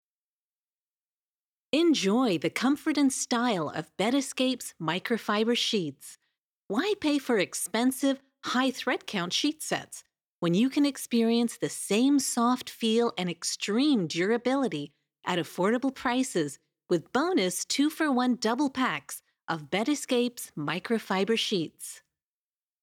Sprechprobe: Werbung (Muttersprache):
Accents: British, North American, Indian, French, Australian, South African